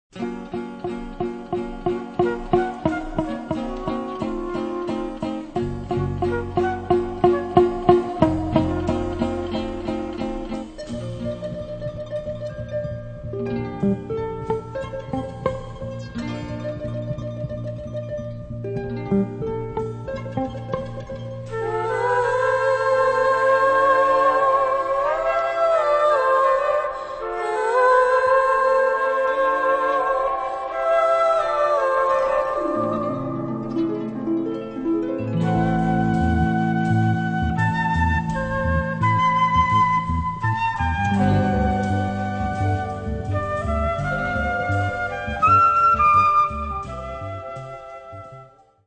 Zwei Soundtracks zu deutschen Aufklärungsfilmen (1968/69)